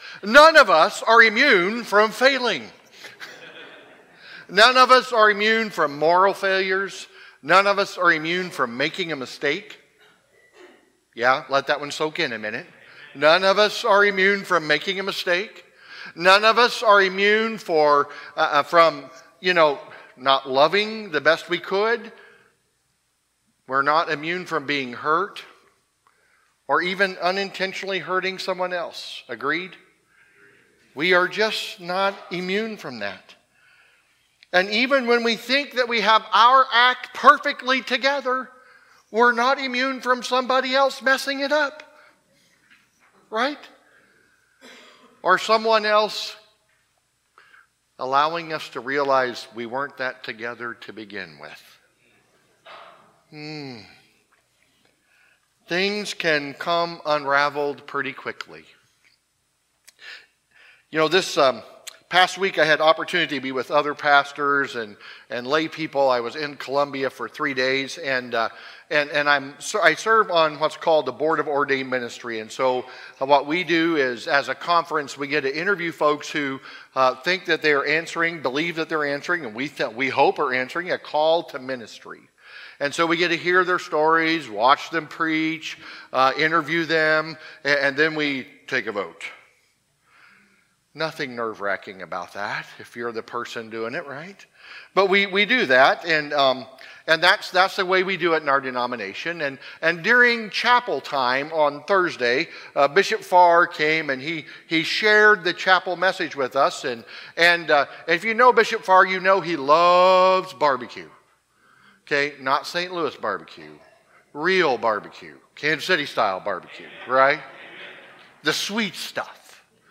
Sermons | Harrisonville United Methodist Church